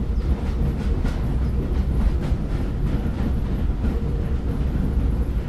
TrainWheelsSFX.wav